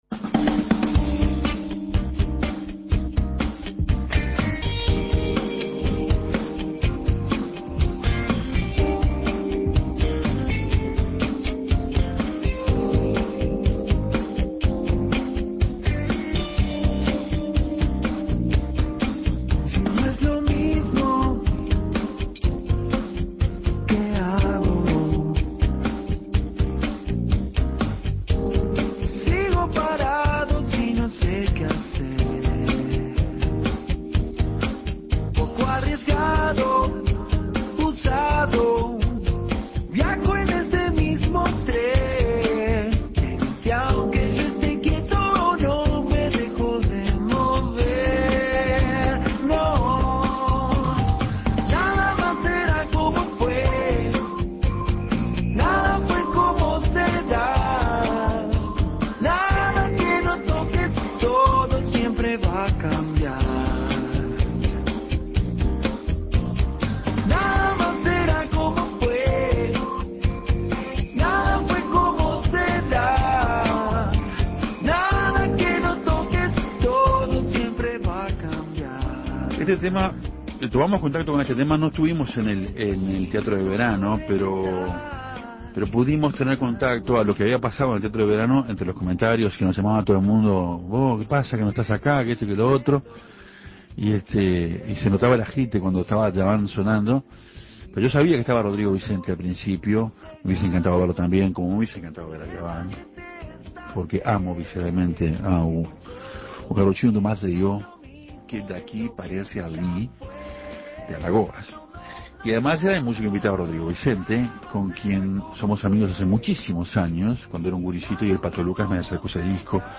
¡con fonoplatea incluída!